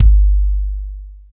KIK H H RA00.wav